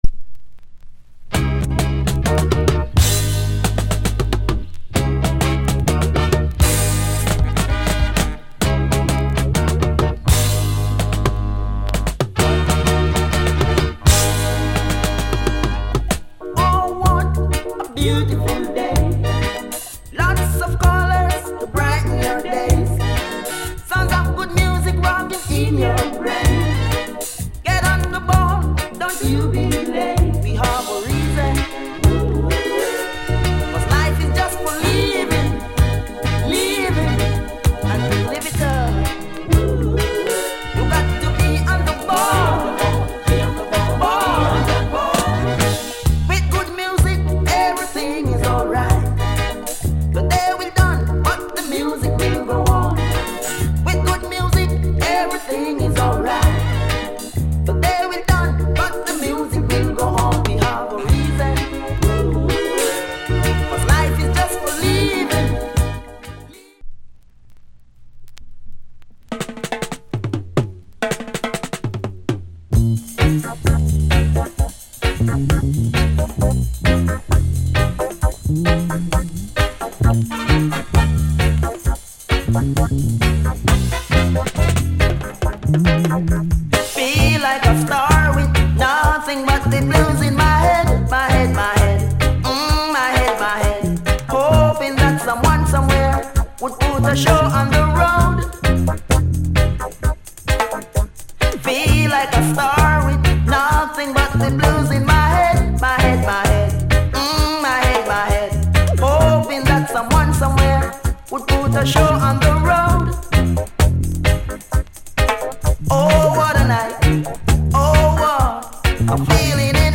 Genre Roots Rock / Male Vocal Group Vocal